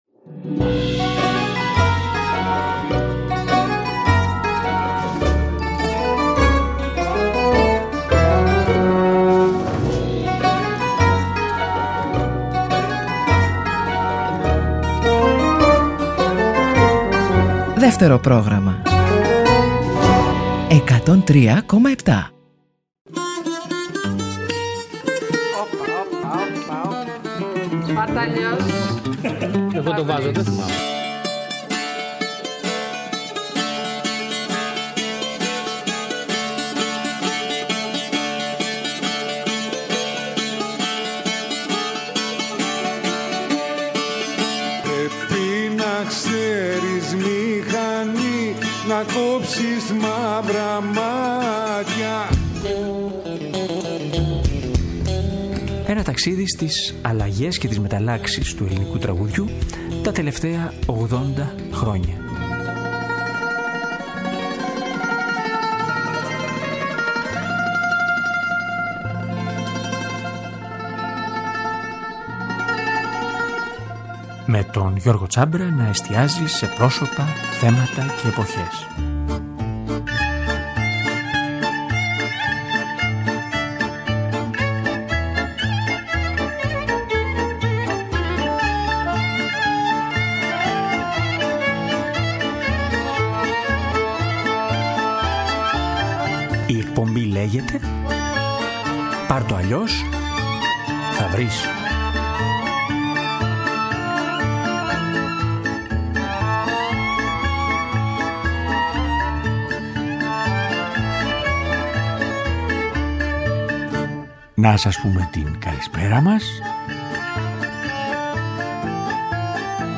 Ρυθμικά τραγούδια
μπαλάντες